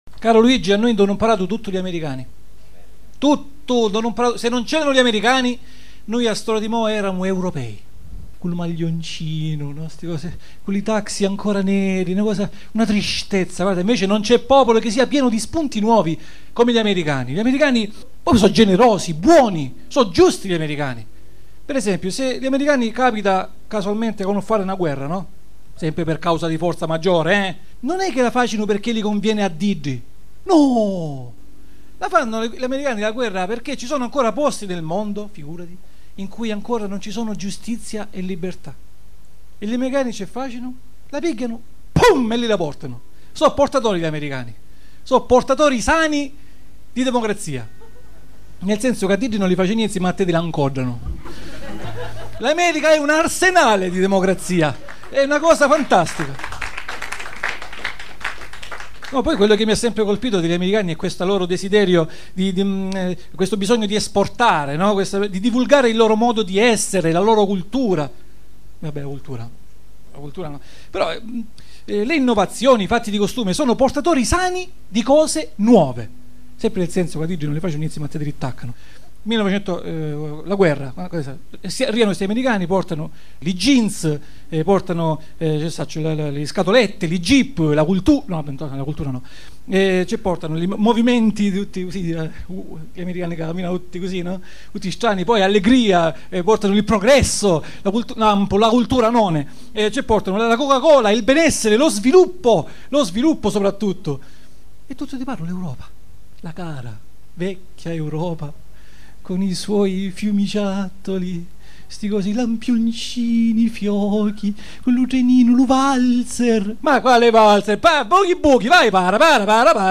Il circolo arci “I Sotterranei” presentano
audio monologo “ L’America